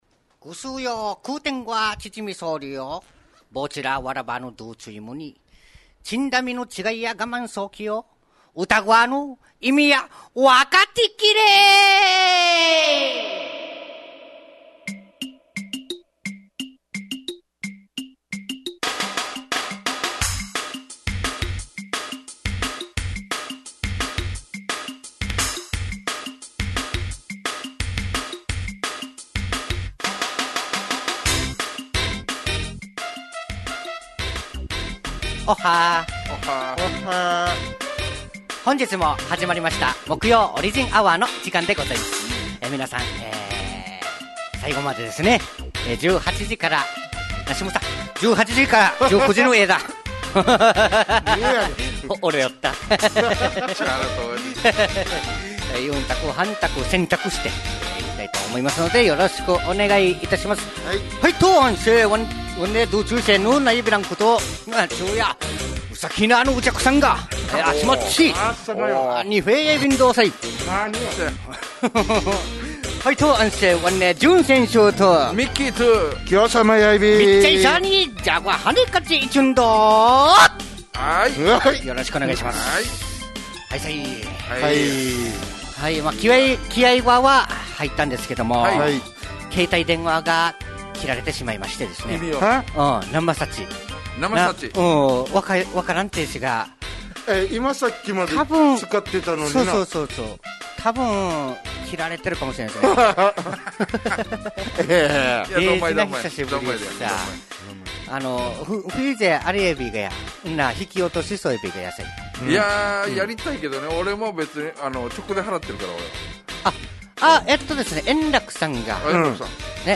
fm那覇がお届けする沖縄のお笑い集団・オリジンメンバー出演のバラエティ番組